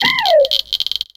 Cri d'Anchwatt dans Pokémon X et Y.